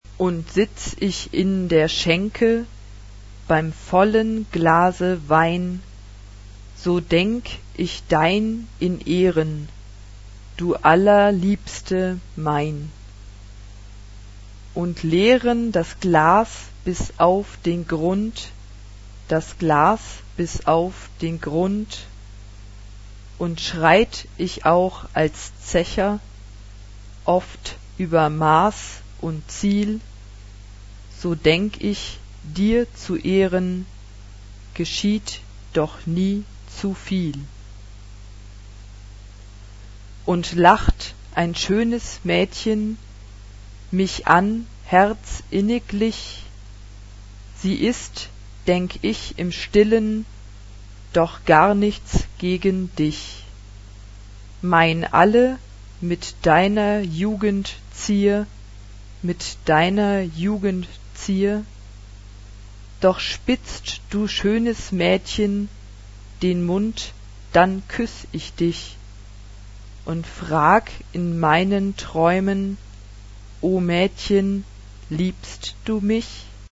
Tonality: C major